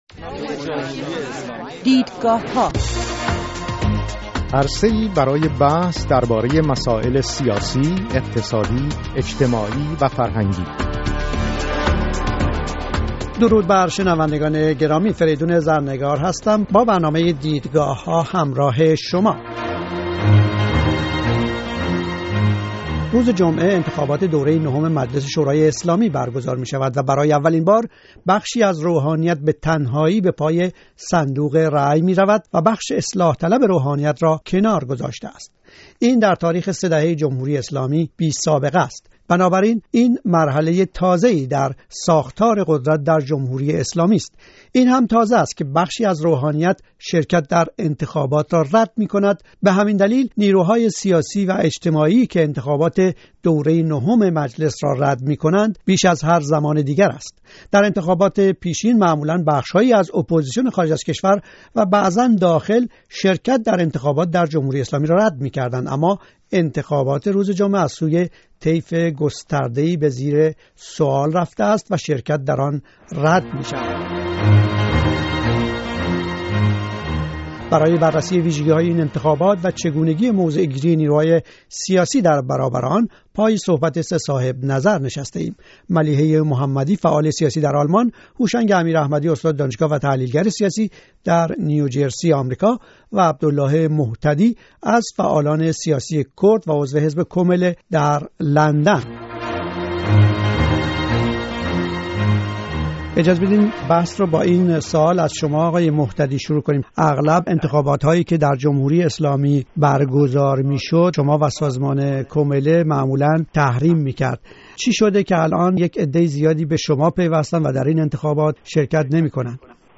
دیدگاه‌ها: مناظره بر سر انتخابات مجلس نهم؛ آیا «تحریم» کارساز است؟